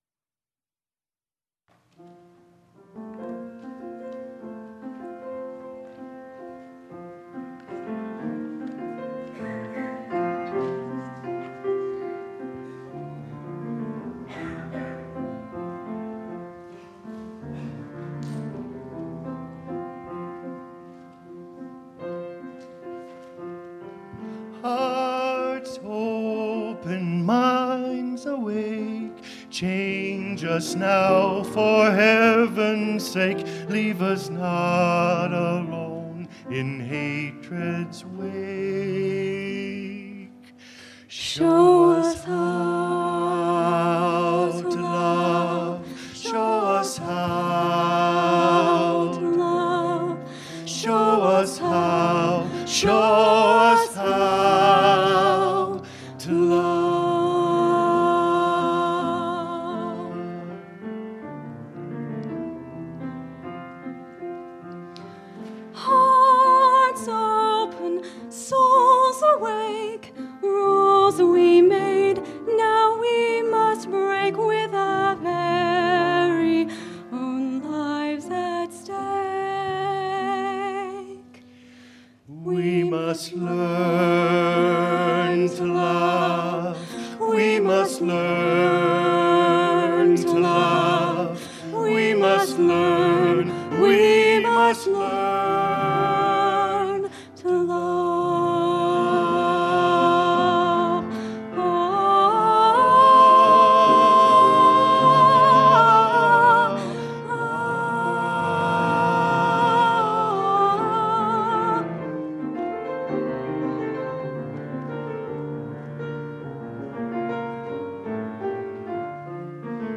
Choir and Instrumental Music